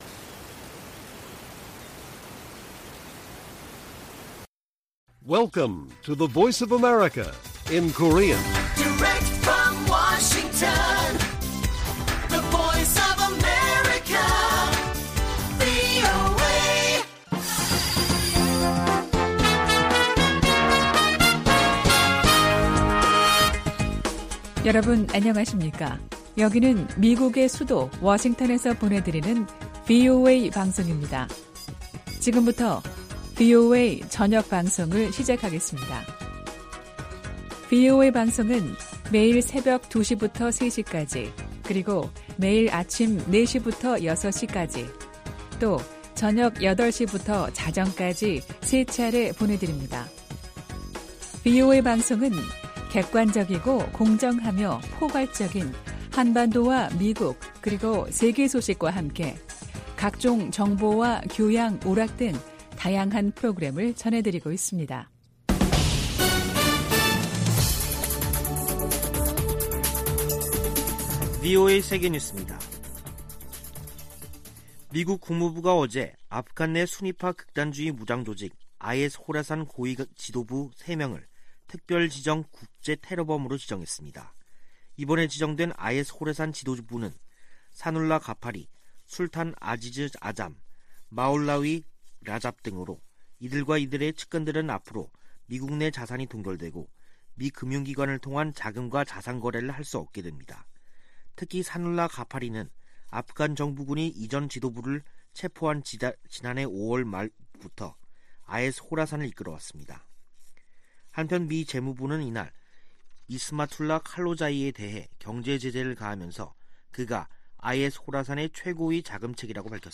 VOA 한국어 간판 뉴스 프로그램 '뉴스 투데이', 2021년 11월 23일 1부 방송입니다. 세계 각국이 북한 해외 노동자 송환 보고서를 유엔 안보리에 보고하도록 돼 있지만 보고 비율은 20% 미만에 그치고 있습니다. 미국이 핵 문제와 관련해 이란과 ‘간접 협상’을 재개하지만 북한과의 협상은 여전히 재개 조짐이 없습니다. 미 국무부는 북한 등 문제 해결에 미,한, 일 3각 공조의 중요성을 거듭 강조하며, 지난주 열린 3국 차관협의회는 매우 건설적이었다고 밝혔습니다.